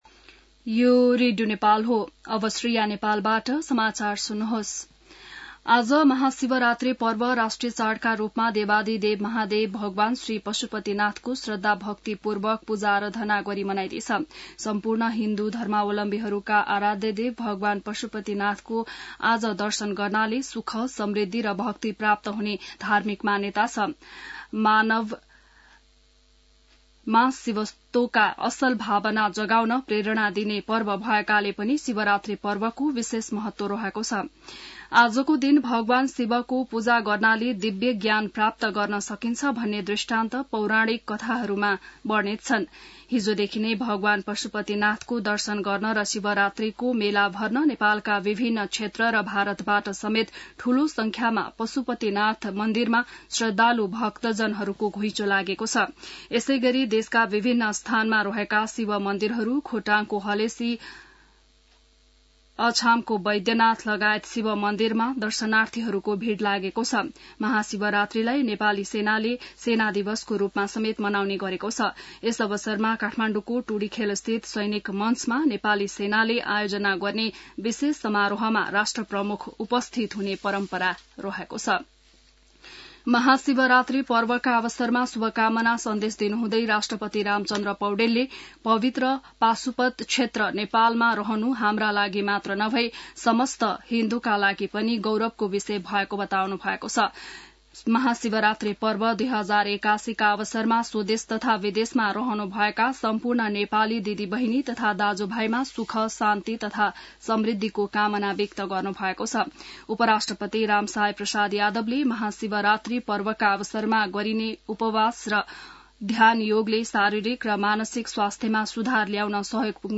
बिहान ६ बजेको नेपाली समाचार : १५ फागुन , २०८१